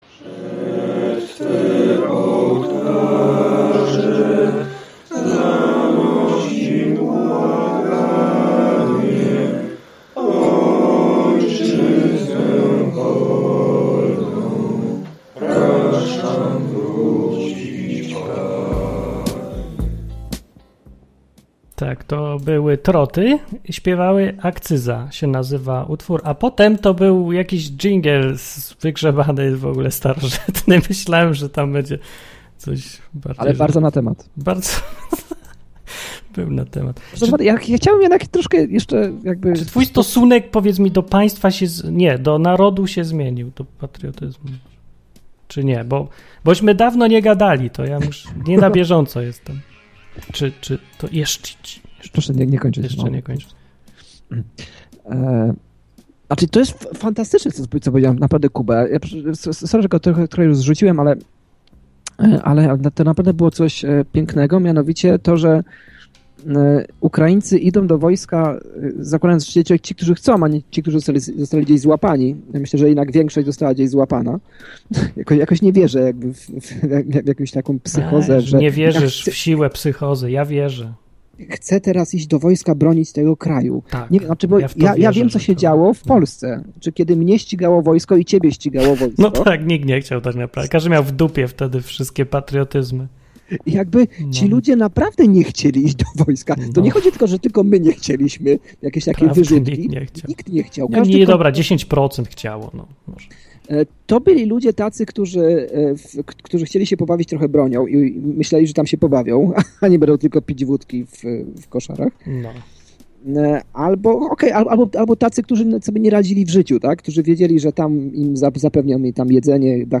Koczowisko Dekandencji to dwu-radiowa audycja, w której od luzu, sarkazmu i ironii wióry lecą.